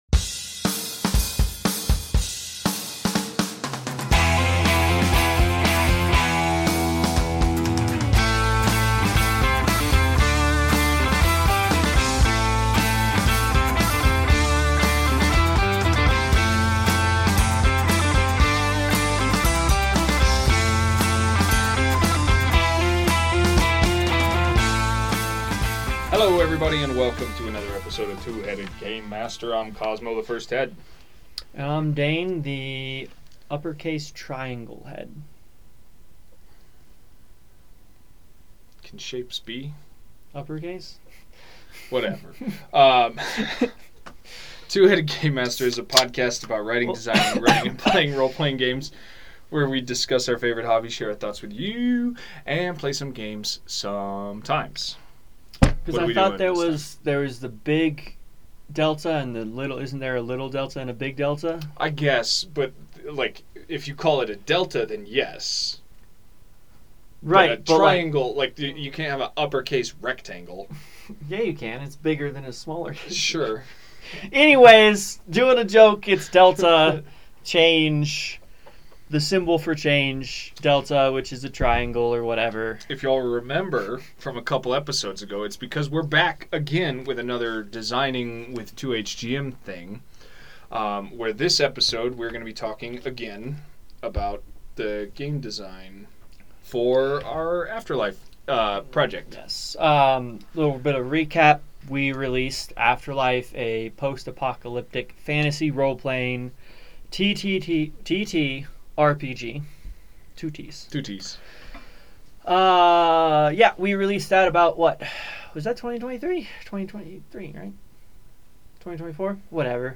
By popular demand we invited the Two-Headed Game-Master duo back into the studio for another designing sesh.